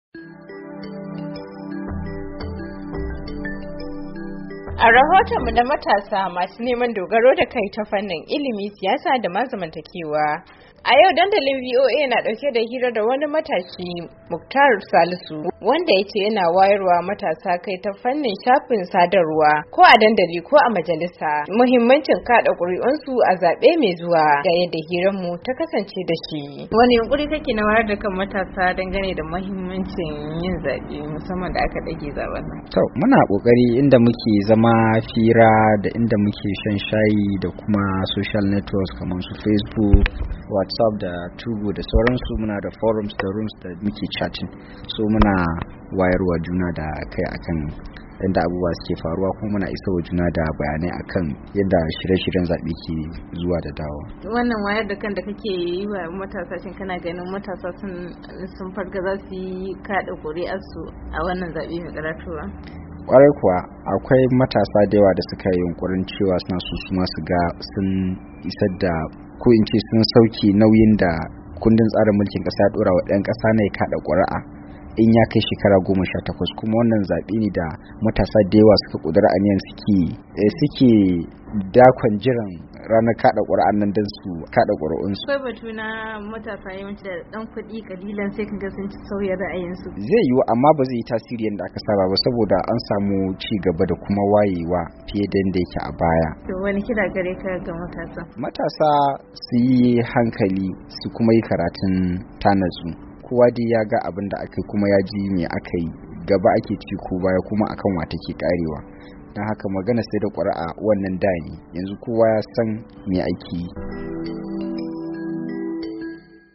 A rohotannin mu na yau na matasa masu dogaro da kai ta fannin ilimi, siyasa da zamantakewa. Filin ya tattauna ne da wani tsohon dan banga wanda yace shi kam yanzu kanshi yaw aye a game da bangar siyasa.